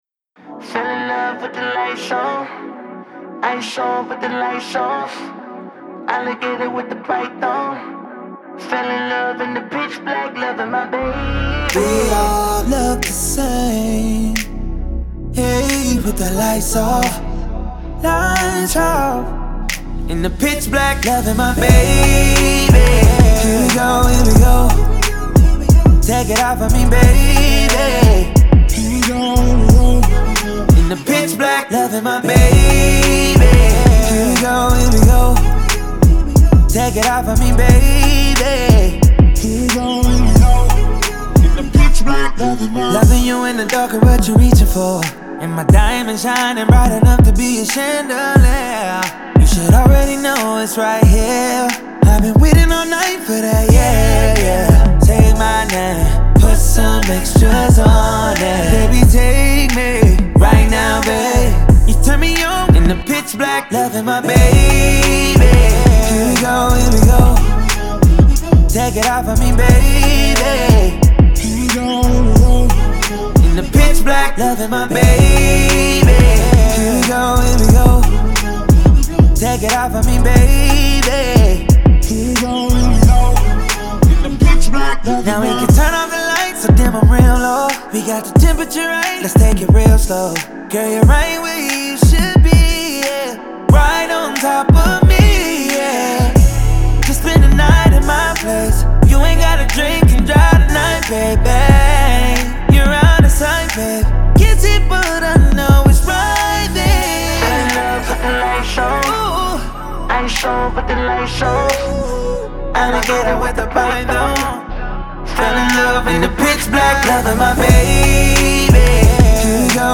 Genre : Rap, Pop